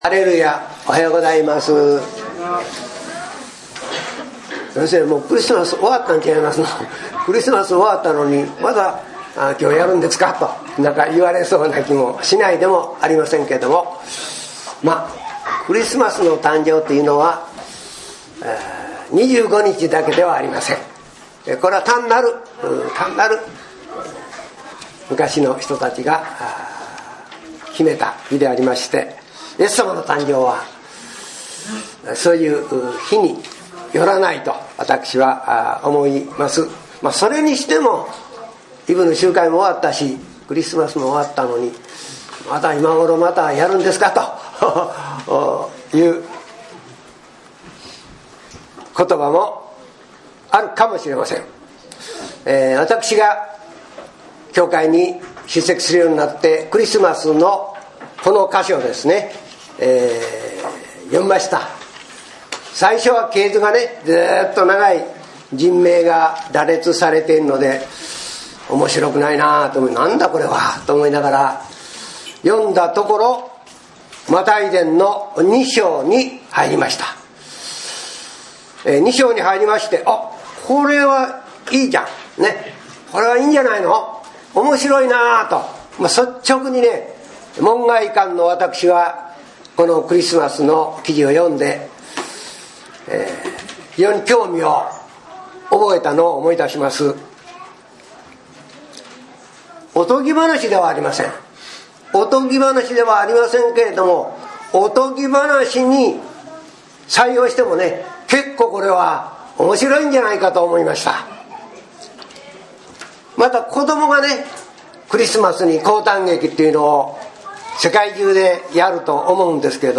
この一事のために | 桑名キリスト教会